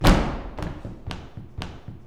115 STOMP2-R.wav